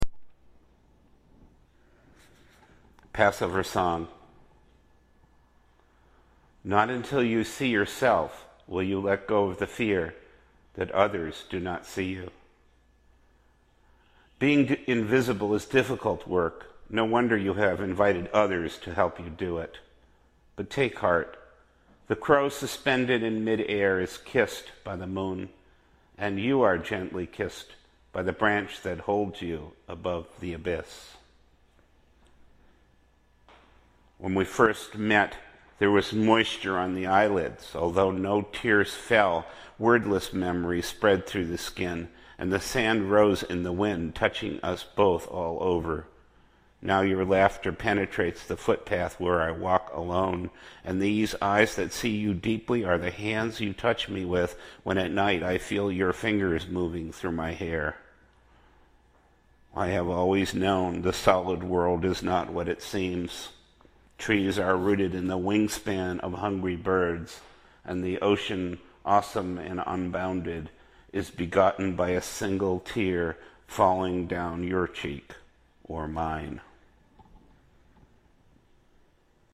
gorgeous piano accompaniment